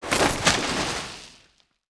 击中5.wav
通用动作/01人物/03武术动作类/击中5.wav
• 声道 單聲道 (1ch)